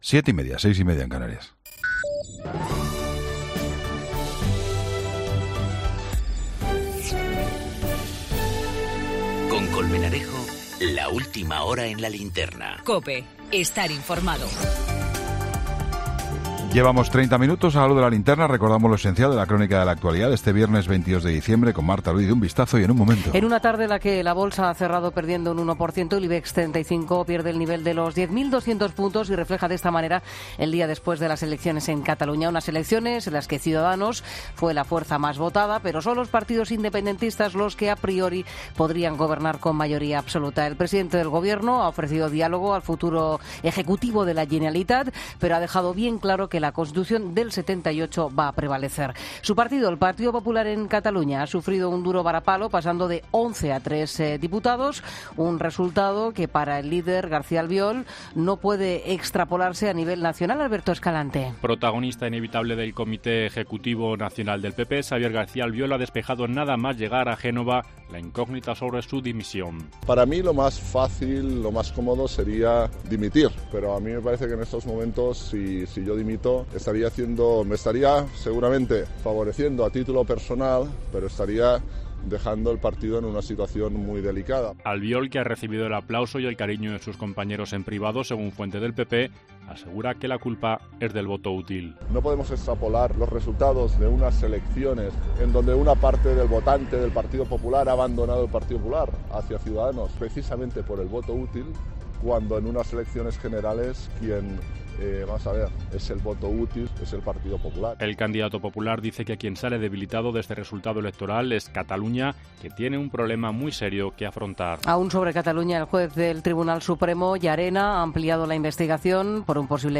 Ronda de corresponsales.
El pronóstico del tiempo